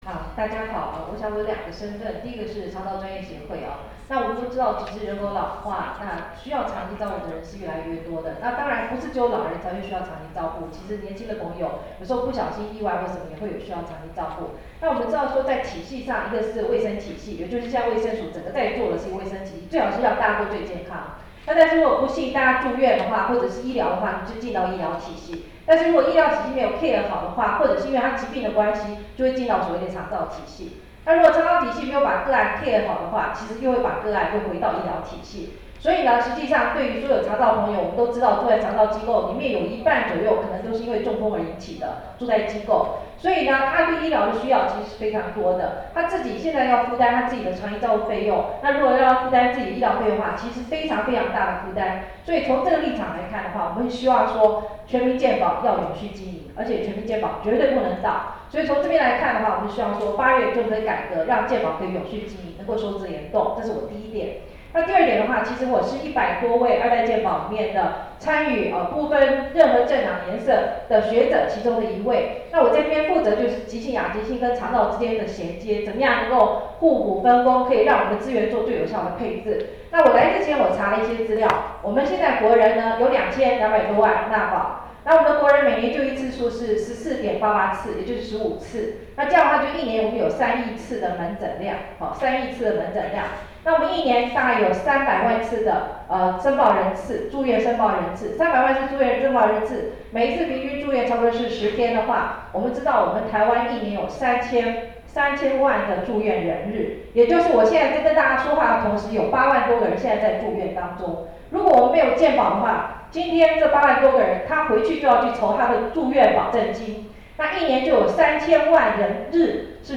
「健保改革不能退怯  修法時機必須掌握」記者會
發言錄音檔